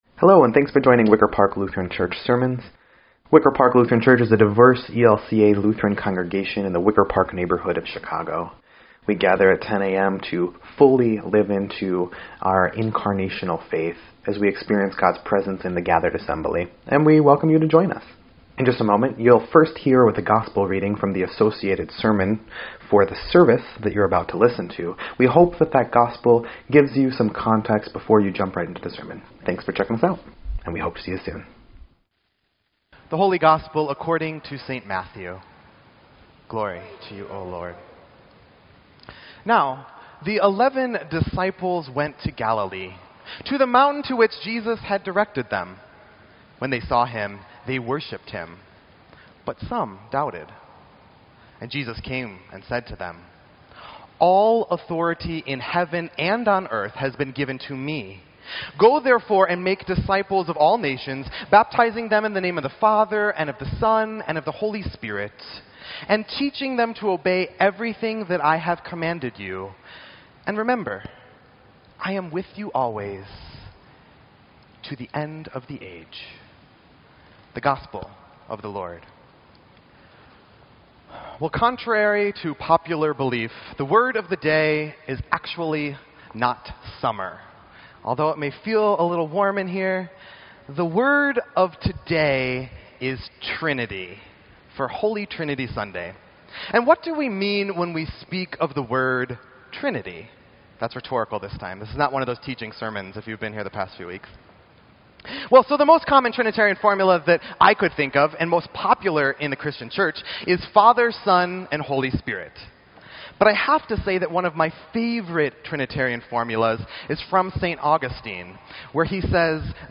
Sermon_6_11_17_EDIT.mp3